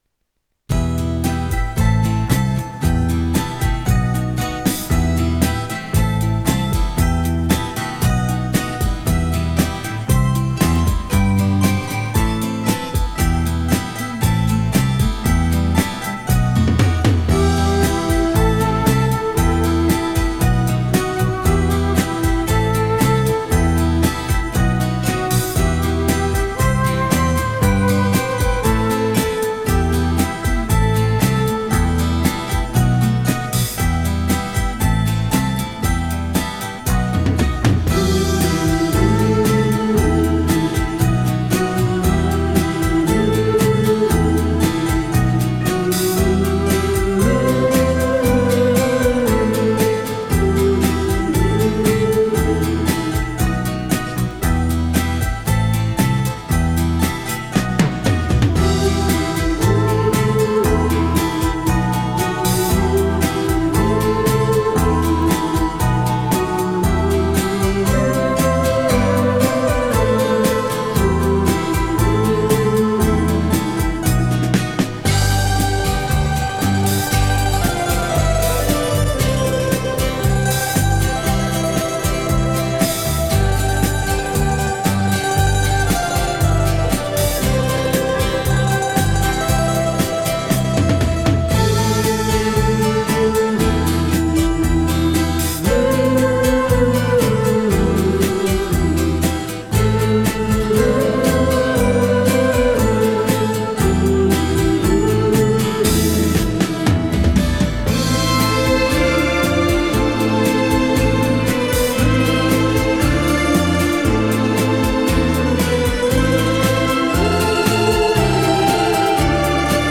Жанр: Latin, Folk, Easy Listening, Accordion